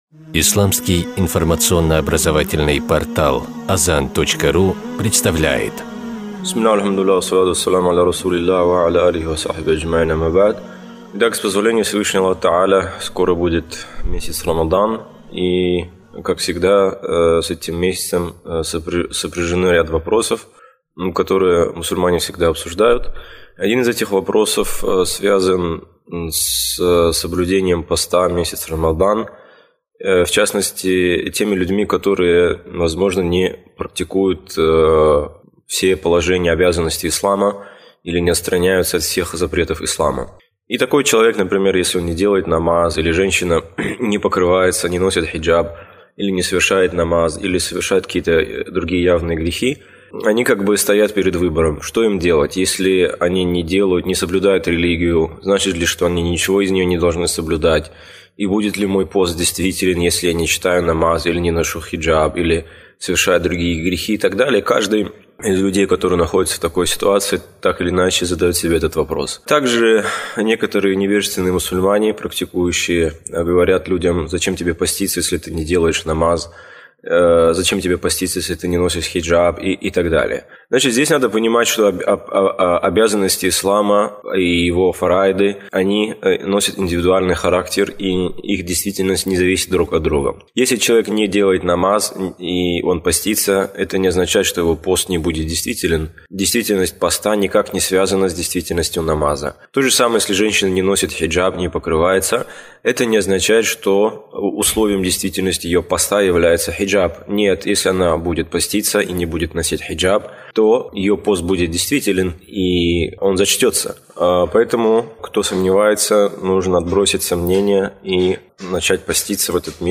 Лектор